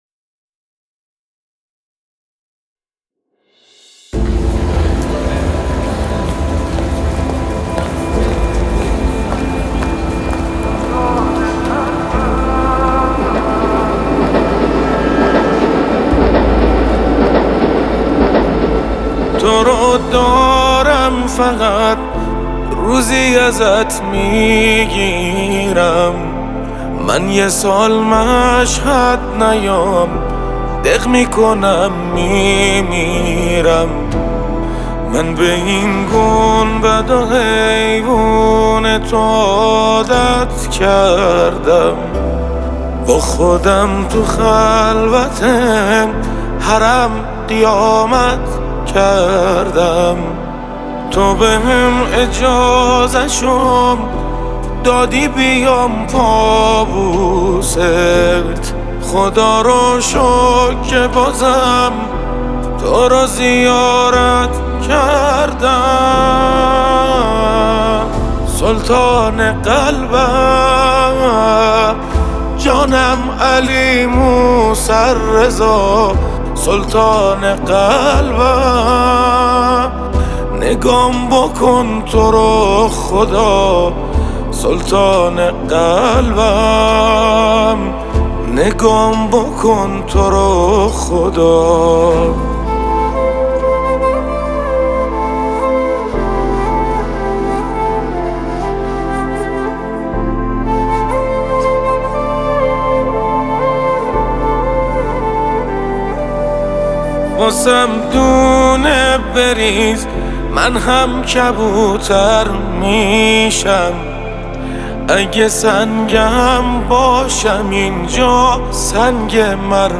نماهنگ بسیار زیبا و شنیدنی